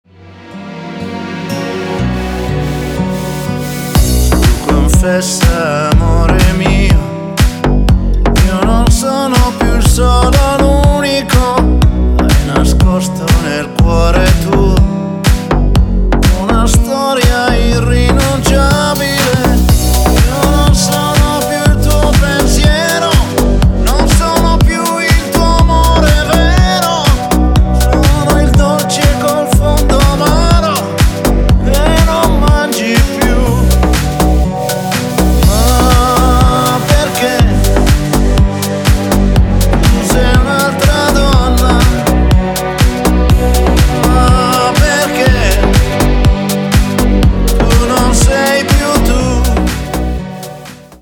Ретро рингтоны